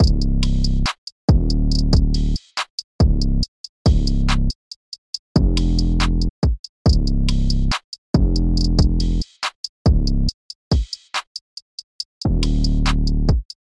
胶带环
描述：快乐
Tag: 140 bpm Trap Loops Drum Loops 2.31 MB wav Key : Unknown FL Studio